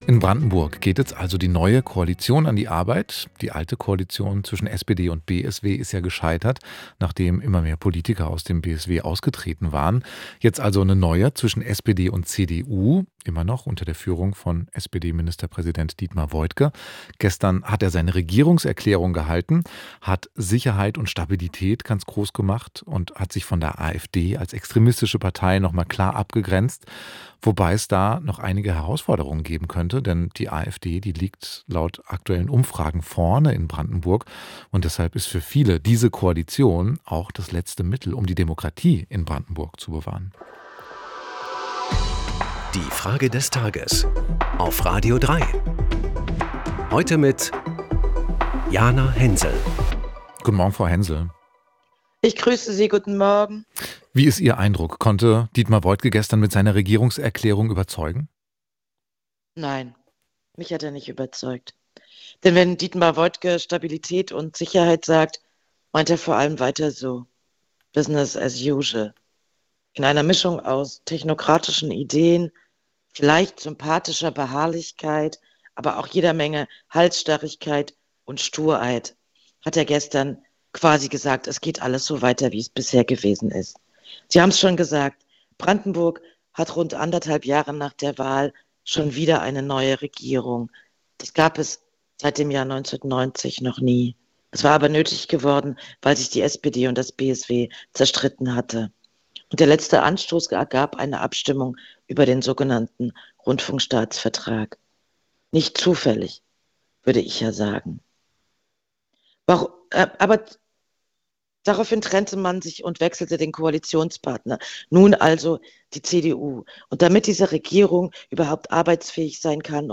Unsere Frage des Tages an die Journalistin Jana Hensel